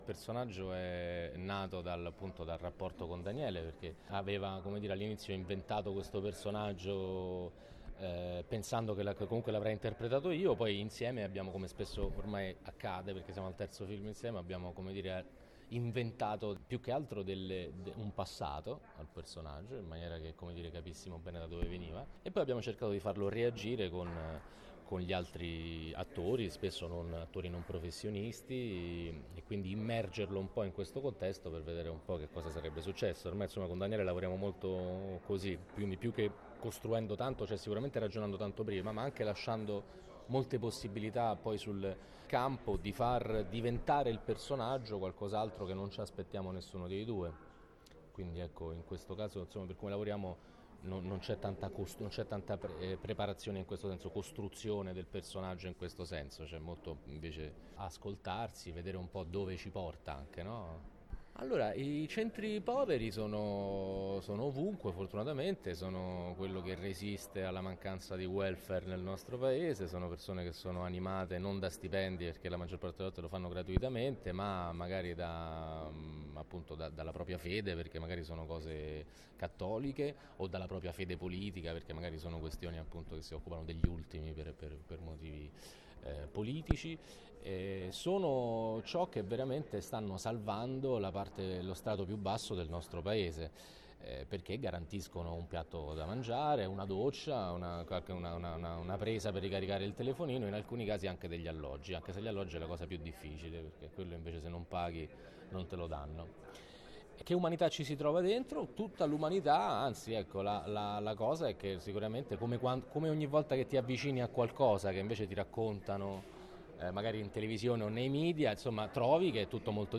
io-sono-tempesta-elio-germano-parla-del-film.mp3